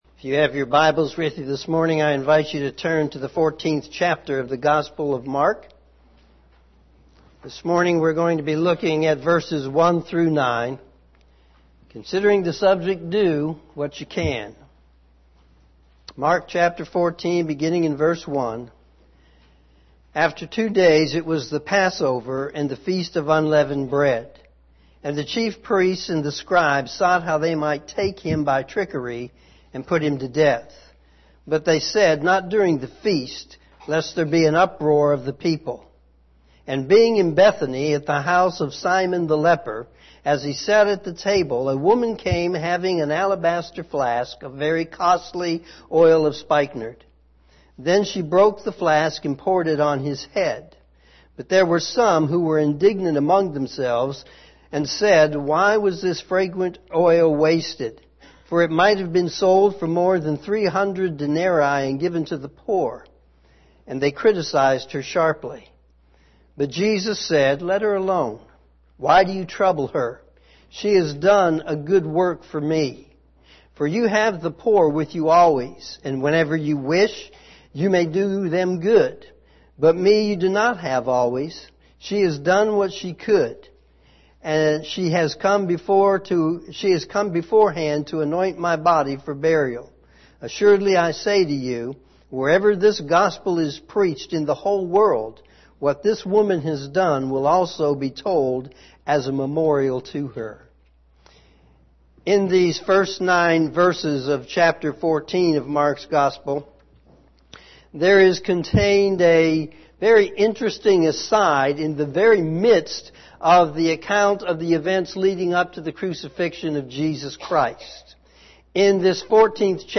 sermon3-3-19am.mp3